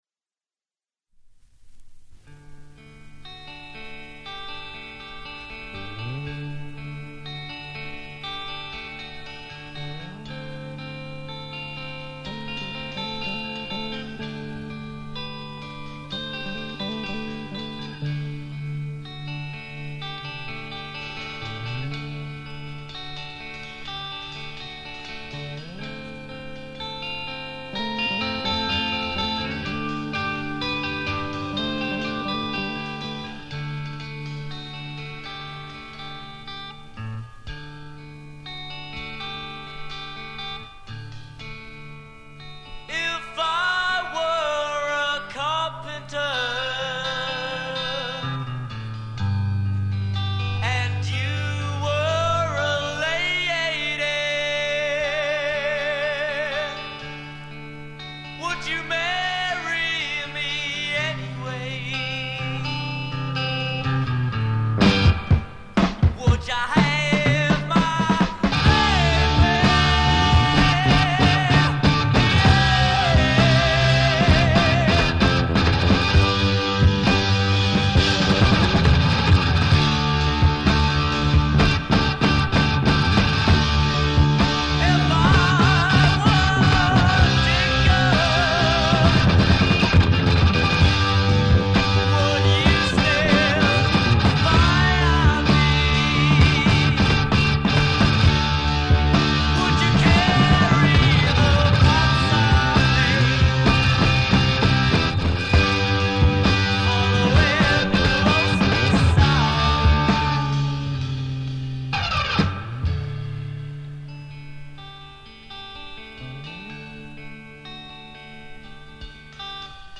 Drums
Guitar
Vocals
Bass